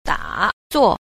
10. 打坐 – dǎzuò – đả tọa